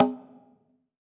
PBONGO HI.wav